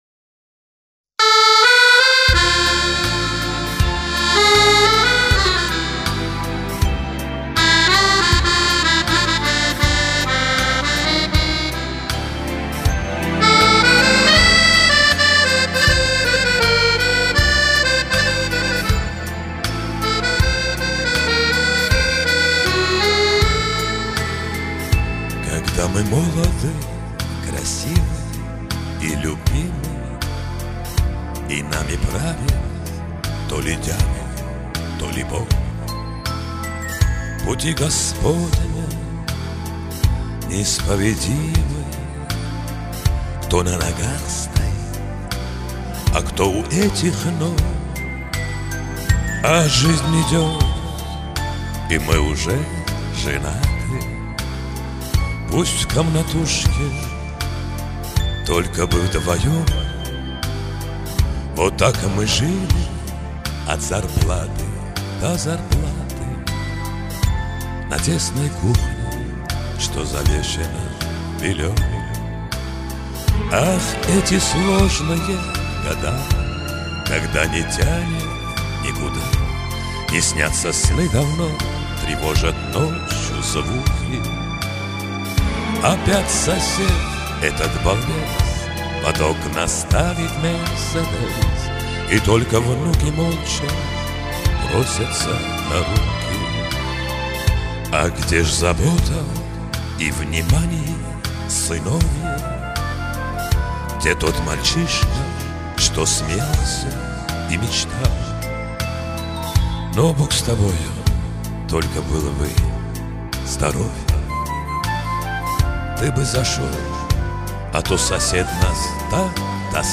Для разрядки песня.